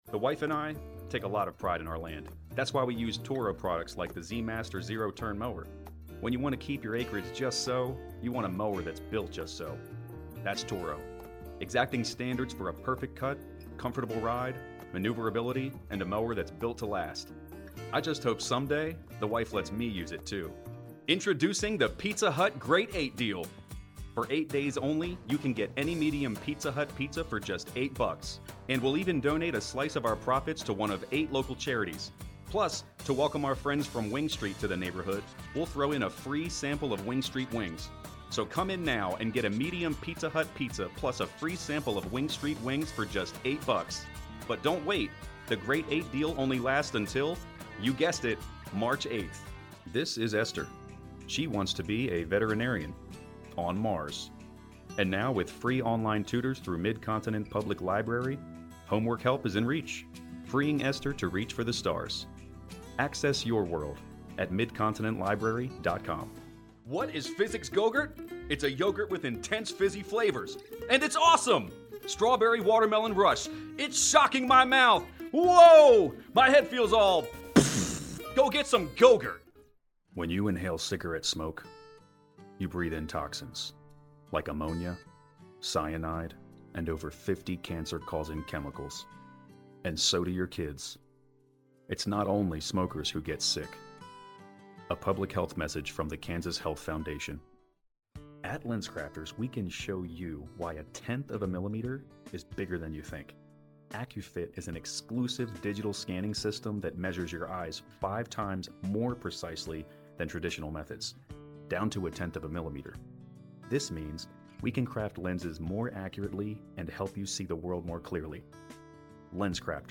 Gender: Male